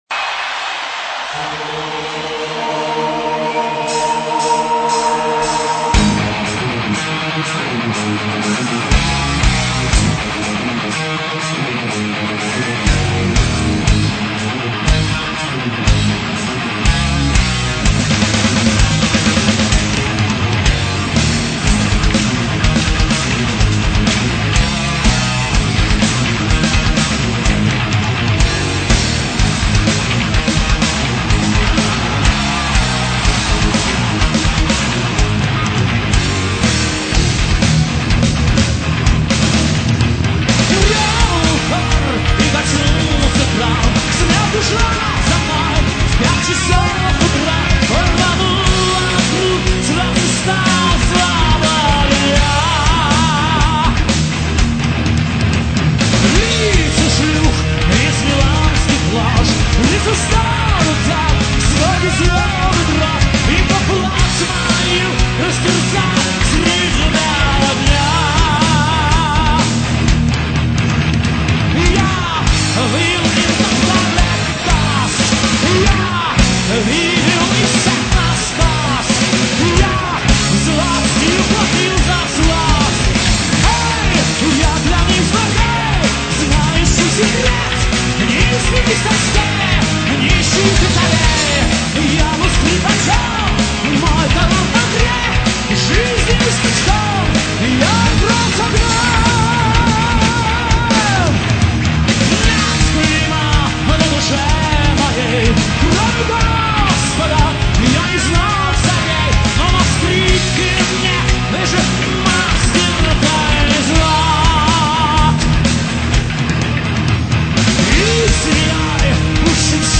гитара
бас-гитара
вокал
барабаны